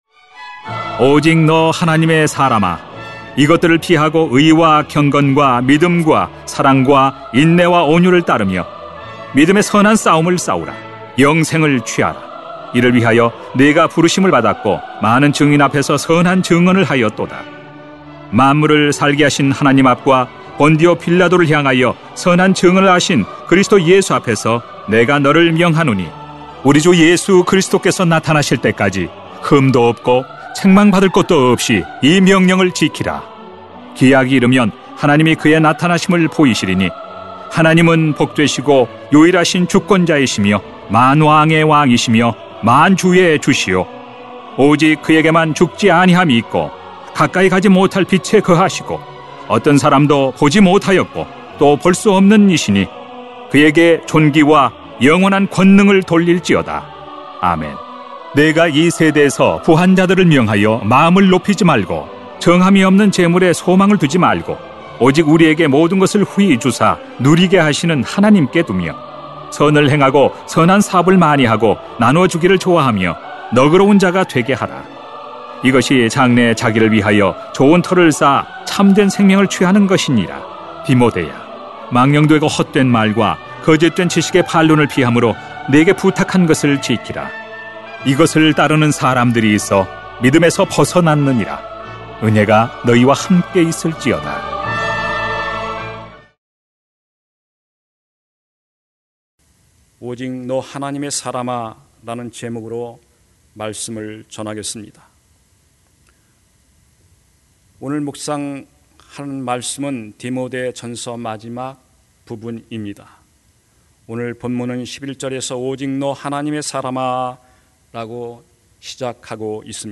[딤전 6:11-21] 오직 너 하나님의 사람아 > 새벽기도회 | 전주제자교회